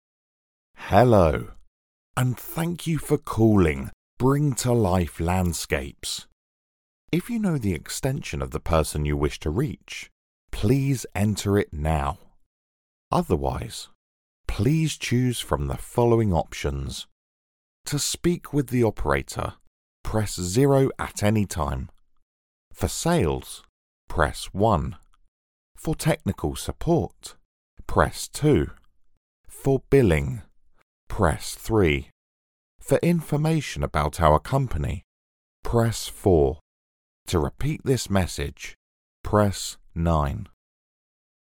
Male
English (British)
Phone Greetings / On Hold
Automated Phone Menu Options